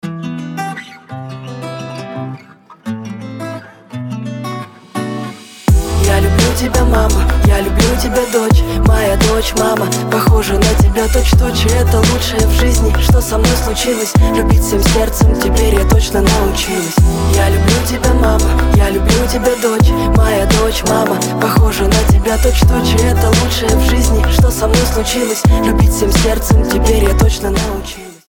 • Качество: 320, Stereo
красивые
добрые